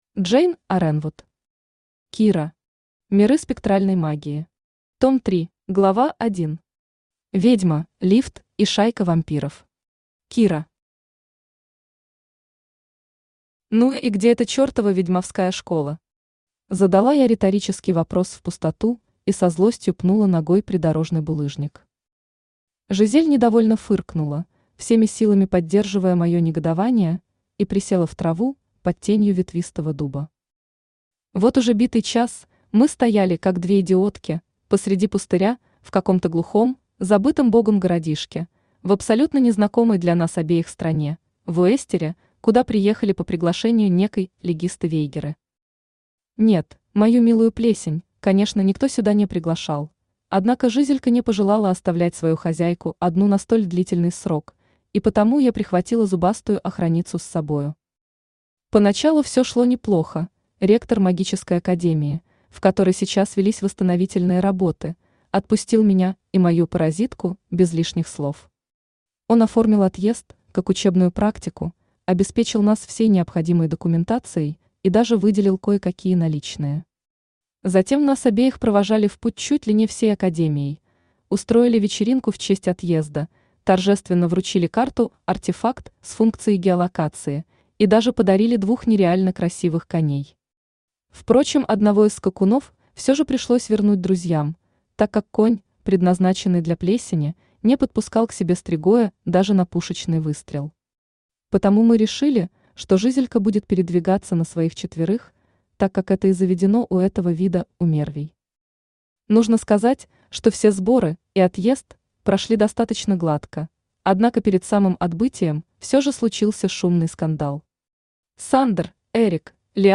Том 3 Автор Джейн Арэнвуд Читает аудиокнигу Авточтец ЛитРес.